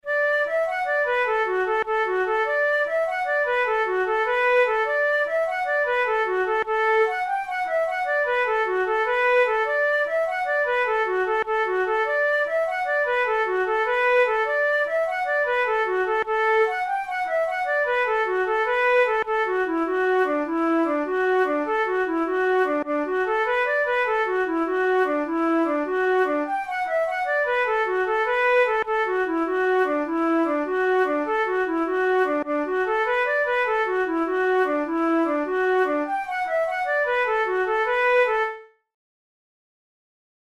InstrumentationFlute solo
KeyD major
Time signature6/8
Tempo100 BPM
Jigs, Traditional/Folk
Traditional Irish jig